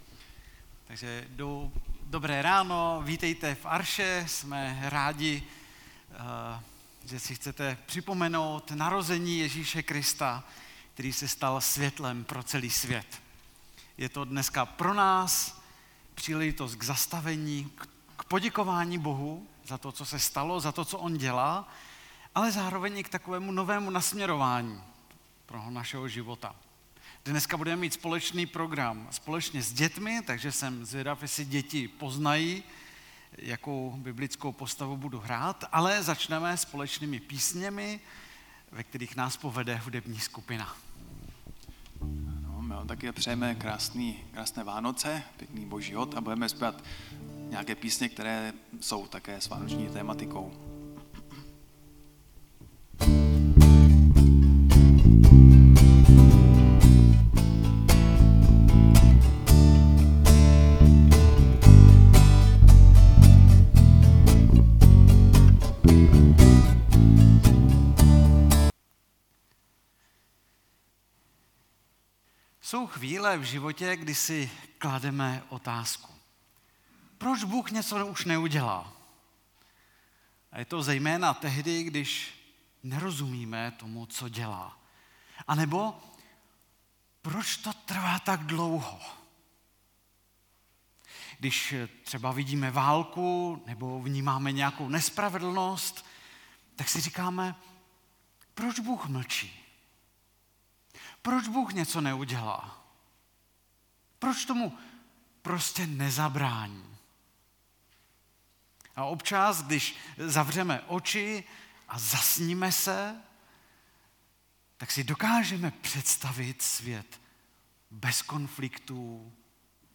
Vánoční bohoslužba